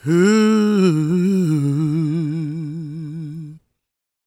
GOSPMALE015.wav